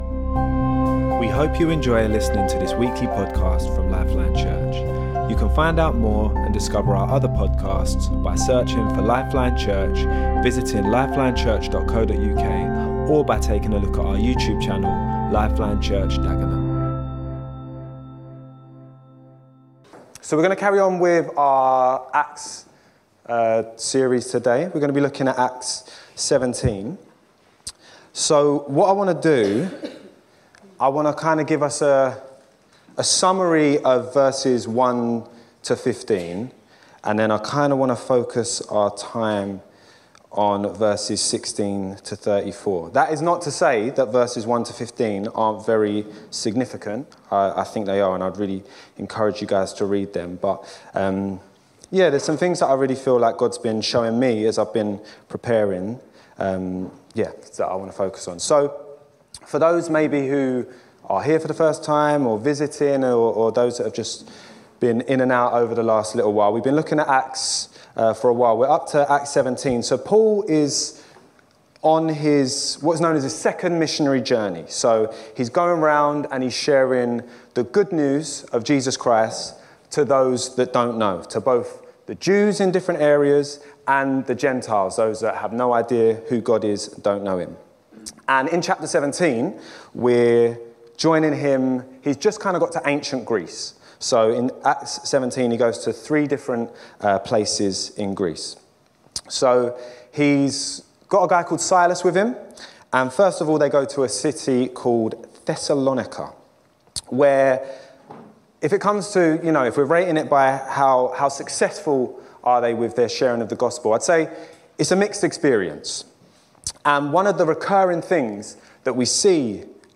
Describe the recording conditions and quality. Our Sunday teaching sometimes follows a theme, a book or explores the ‘now’ word of God to us as a community.